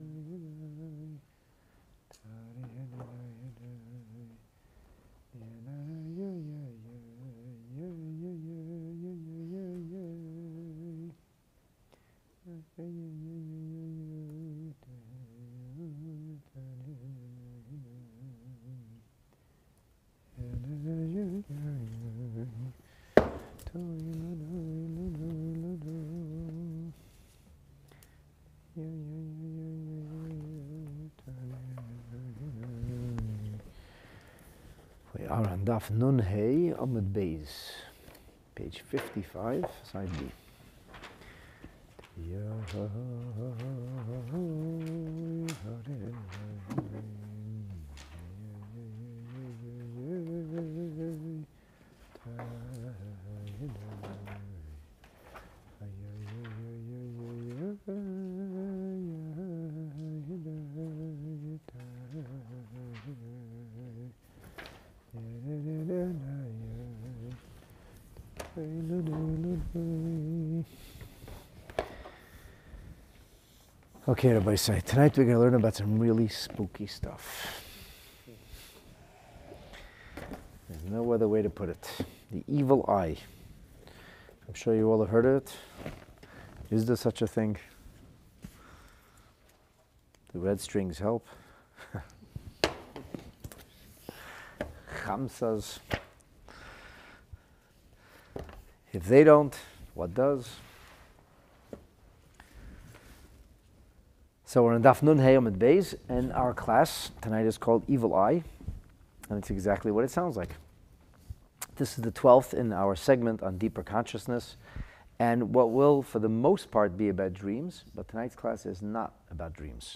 It is the twenty-sixth consecutive class on the Ninth Chapter, AKA Perek HaRoeh. "EVIL EYE" is a fascinating treatment of the phenomenon known as Ayin Hara.